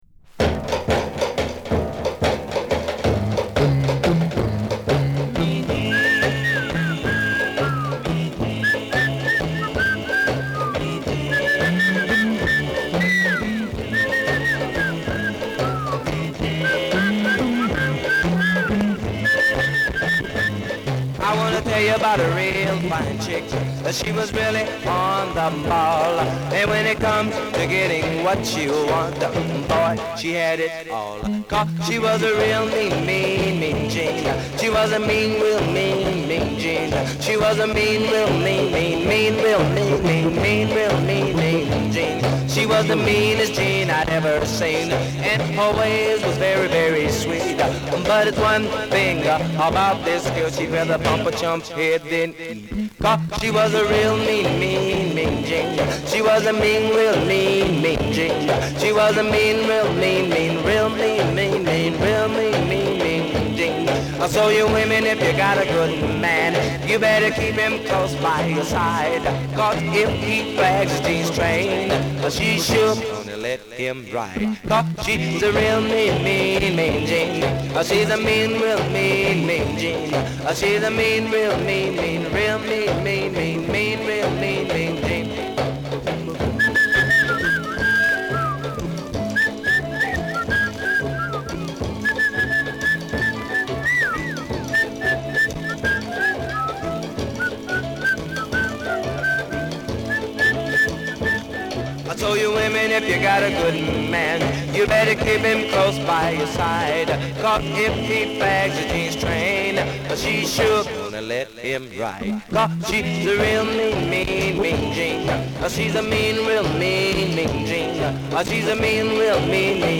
盤面そのものは割ときれいですが、プレスの状態が悪く（おそらく全ての盤も同様）、所々でノイズがはいります。